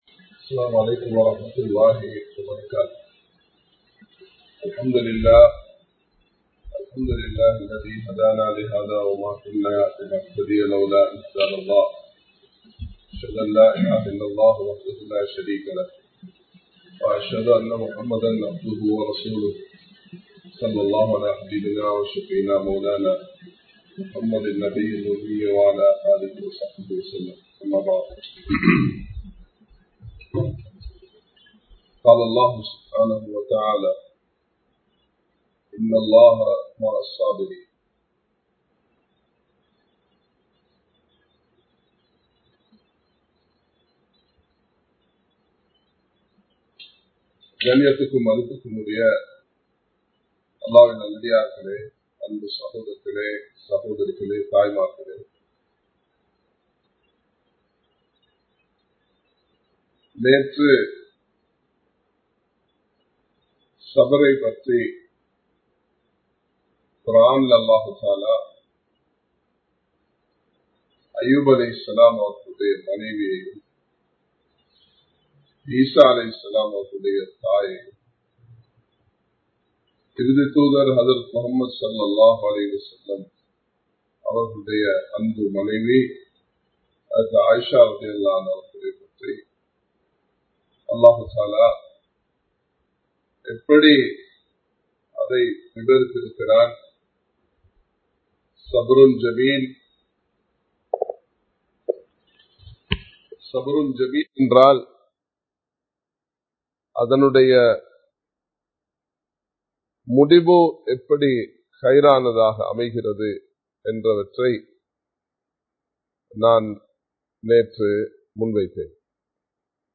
Live Stream